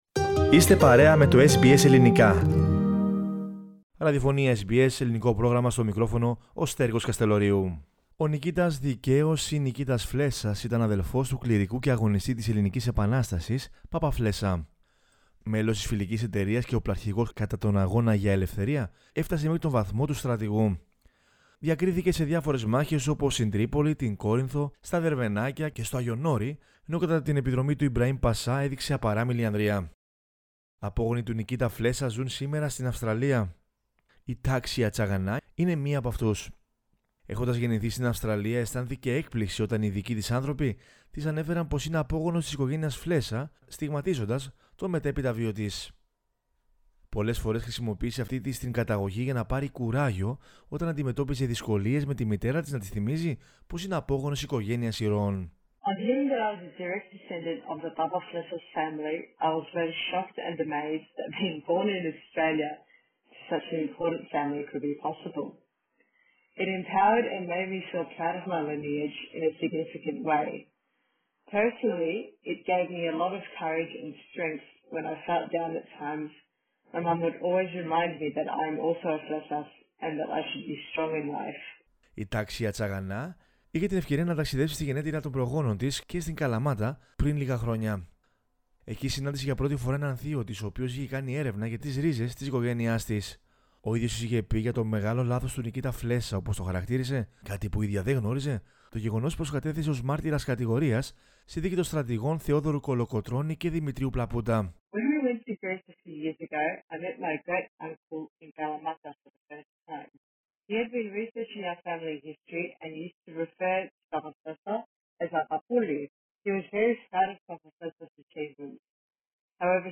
Στο πλαίσιο των συνεντεύξεων με απόγονους Ελλήνων αγωνιστών του 1821